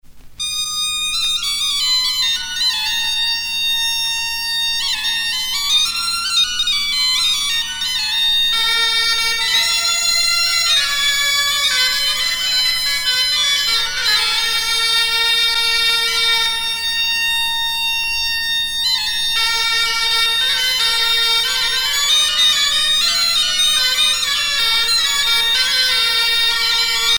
danse : kas-a-barh
Pièce musicale éditée